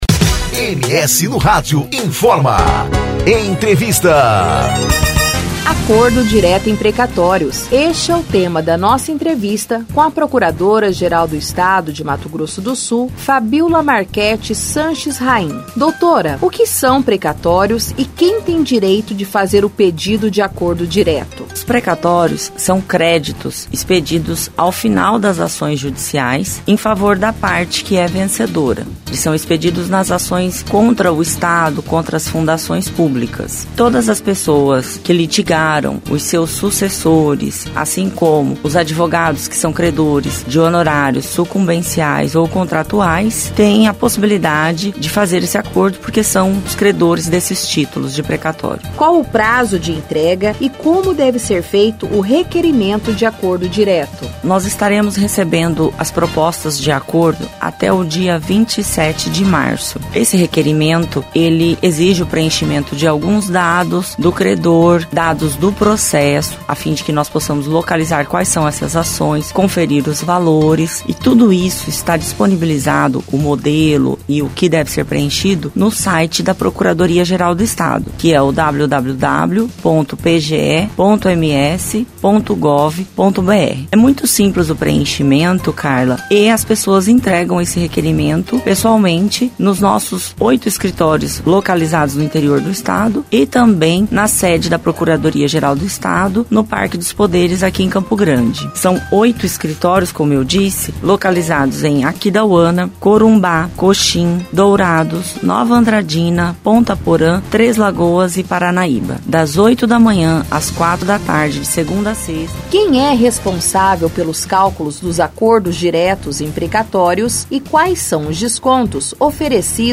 ENTREVISTA: Procuradora-Geral do Estado, Fabíola Marquetti, fala sobre precatórios
Acordo Direto em precatórios este é o tema da nossa entrevista com a procuradora-Geral do Estado, Fabíola Marquetti Sanches Rahim.